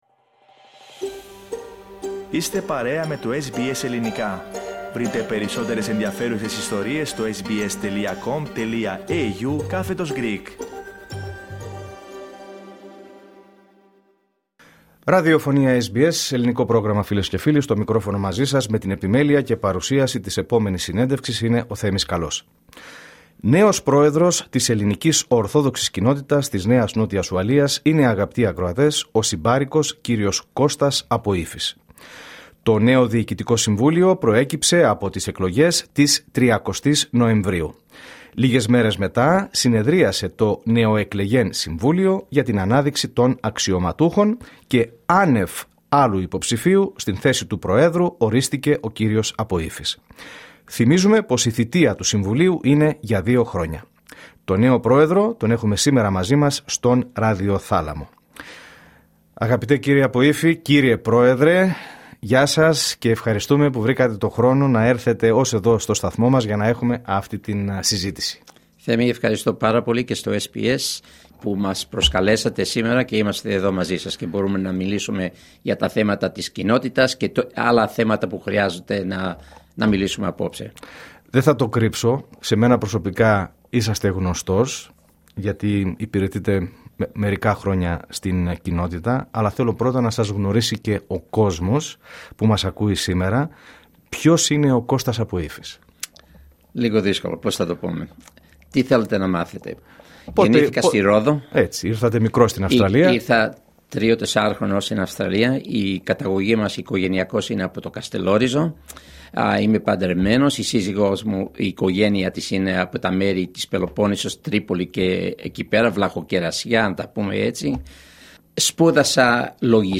Ο νέος πρόεδρος βρέθηκε στον ραδιοθάλαμο του SBS Greek για την πρώτη του εκτενή συνέντευξη μετά την εκλογή του.